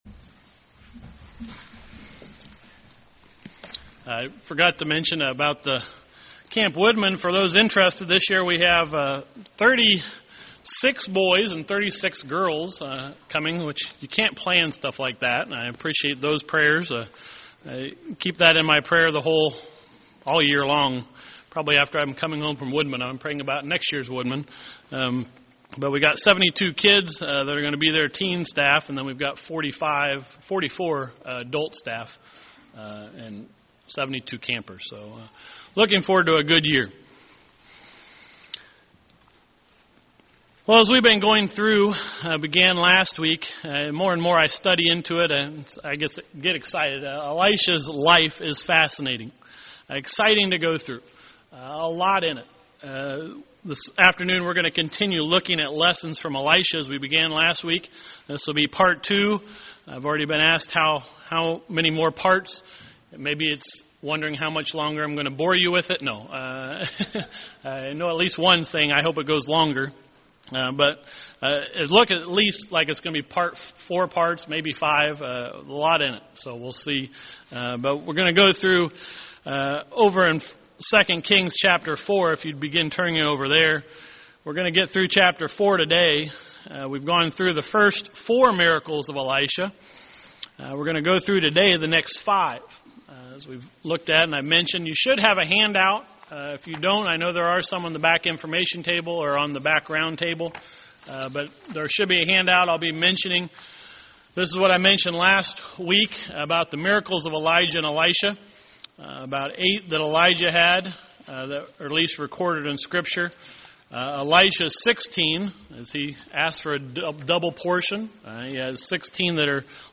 Given in Northwest Indiana Elkhart, IN
UCG Sermon Studying the bible?